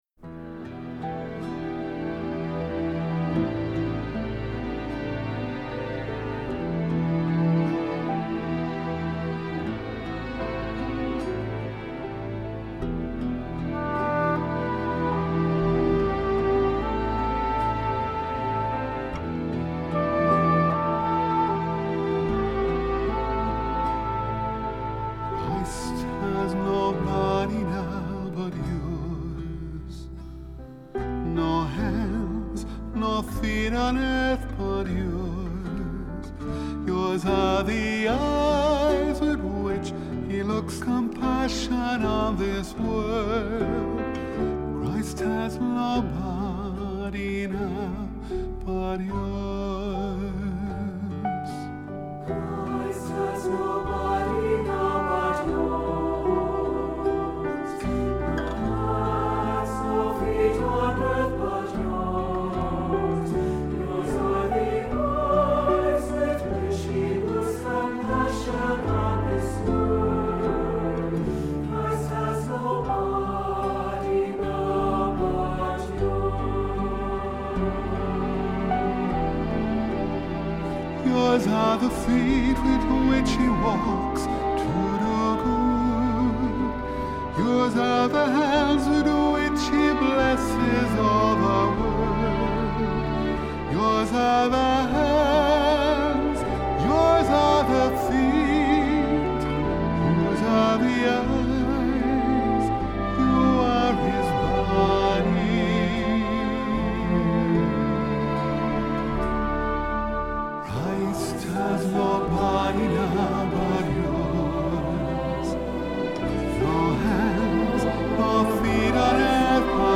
Accompaniment:      Keyboard, C Instrument
Music Category:      Christian
For cantor or soloist.